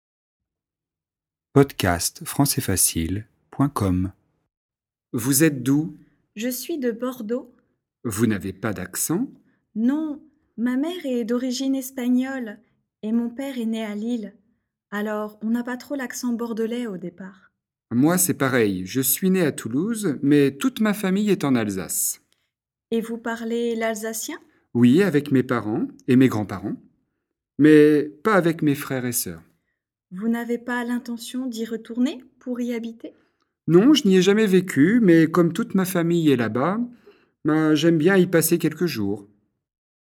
Dialogue FLE et exercice de compréhension, niveau intermédiaire (A2) sur le thème ville et région.